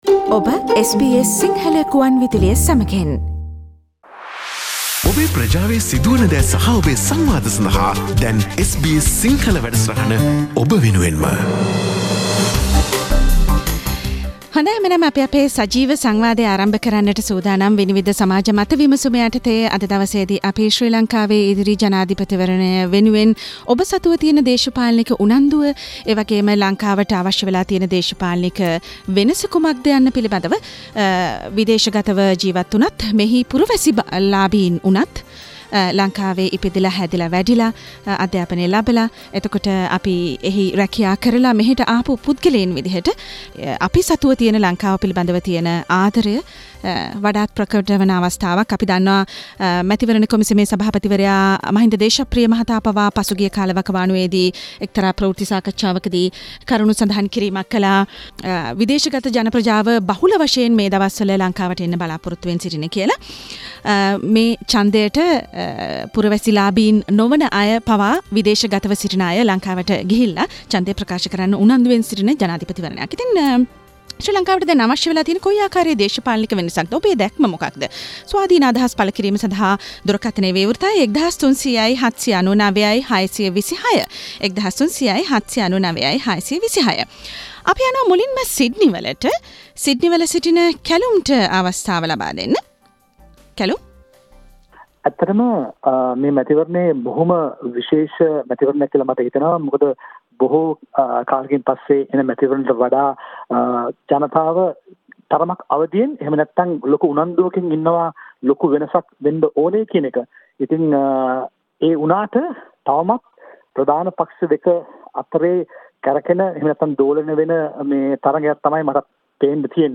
ශ්‍රී ලංකාවට දැන් අවශ්‍යව ඇත්තේ කුමන ආකාරයේ දේශපාලනික වෙනසක්ද? ඕස්ට්‍රේලියාවේ ජිවත්වන ශ්‍රී ලාංකිකයින් SBS සිංහල සේවයට කියූ දෑ
මෙවර SBS සිංහල සේවයේ 'විනිවිද' මාසික සමාජ මත විමසුම වෙන්වූයේ ශ්‍රී ලංකාවේ ඉදිරි ජනාධිපතිවරණයත් සමඟම මතුව ඇති දේශපාලනික උණුසුම සමඟින්ම වඩාත් කතාබහට ලක්ව ඇති ශ්‍රී ලංකාව අවශ්‍ය දේශපාලනික දැක්ම පිළිබඳව ඕස්ට්‍රේලියාවේ ජිවත්වන ශ්‍රී ලාංකික ප්‍රජාවගේ අදහස් විමසීමටයි.